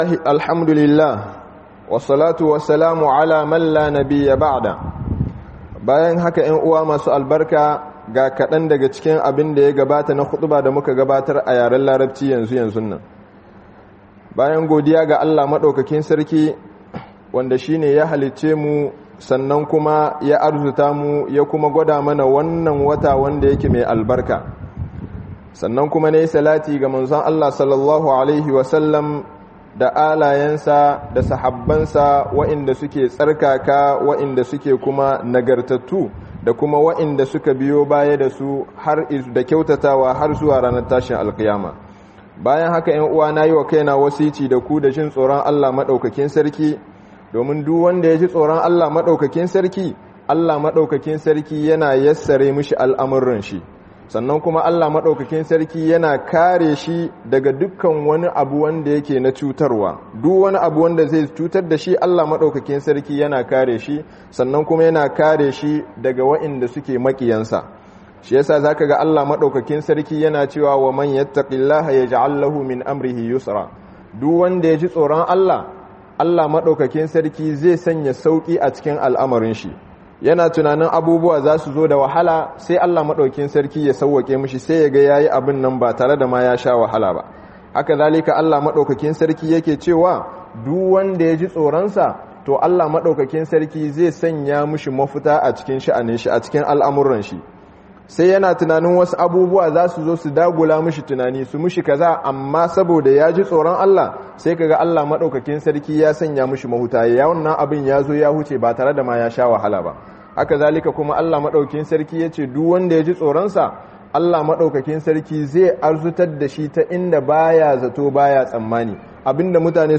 Allah ne yace ayi azumi - Hudubobi